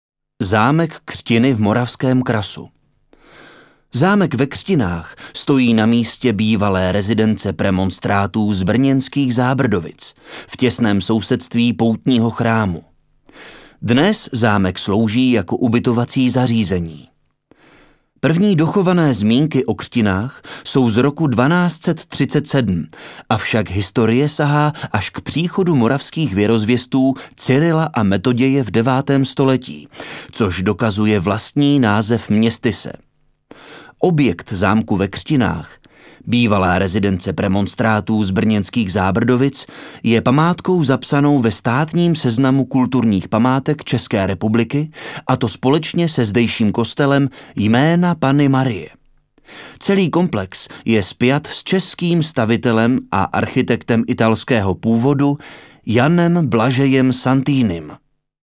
voice over:
ukazka-voice-over.mp3